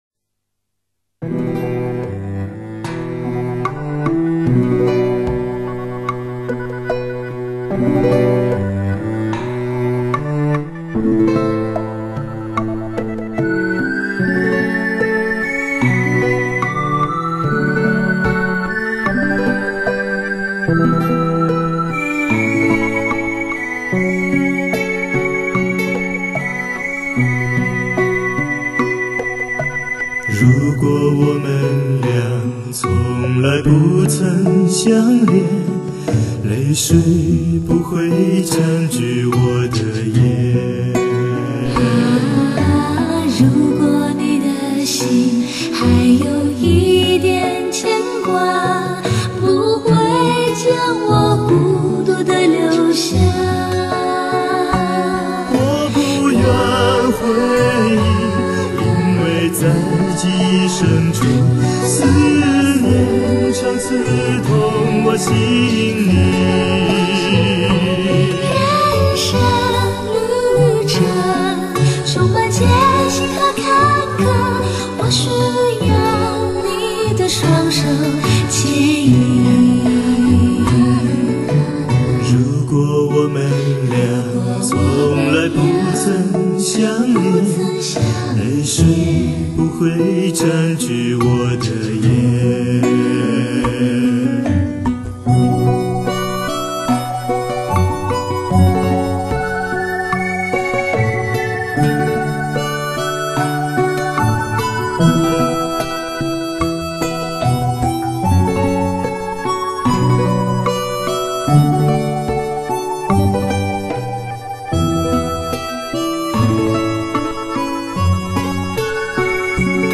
如此经典的旋律，如此新鲜的诠释。